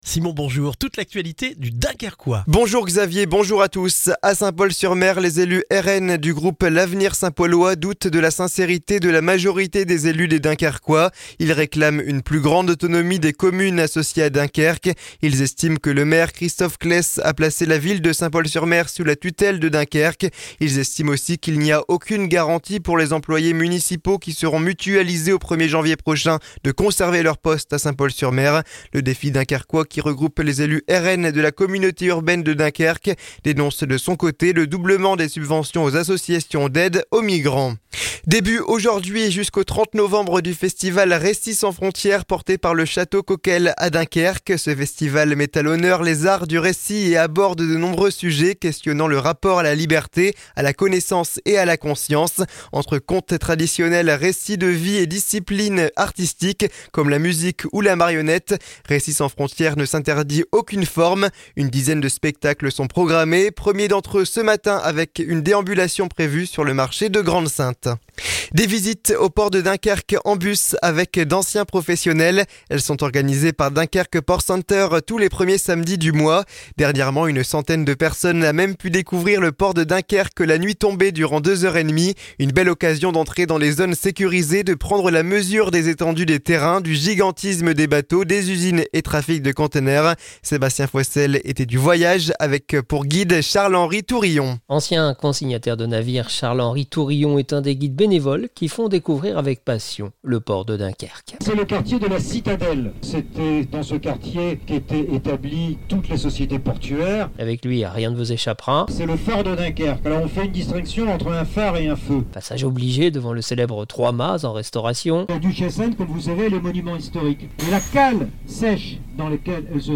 Le journal du jeudi 14 novembre dans le Dunkerquois